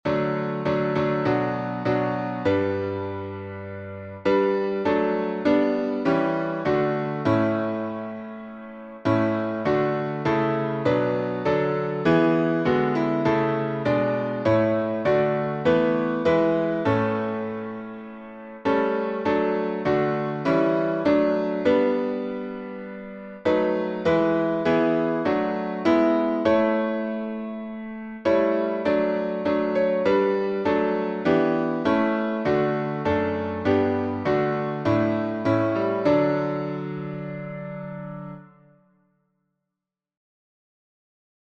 Key signature: D major (2 sharps) Time signature: 4/4